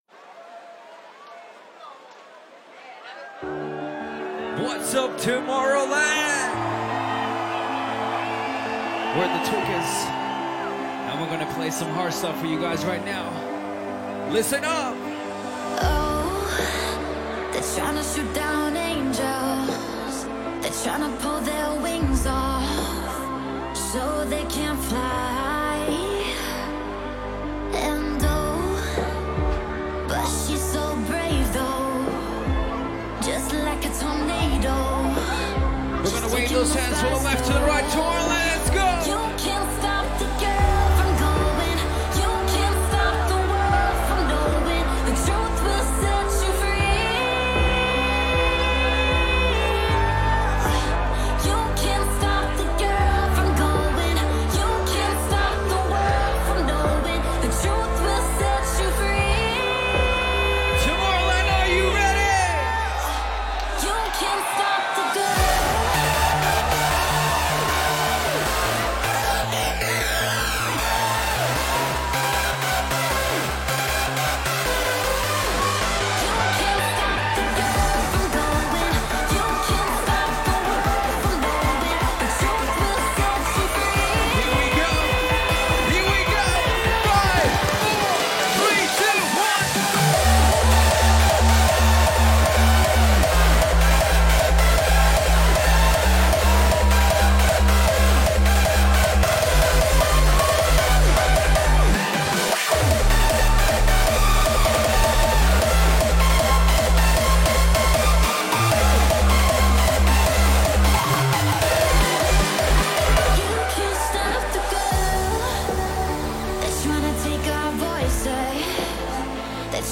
Genre: Hardstyle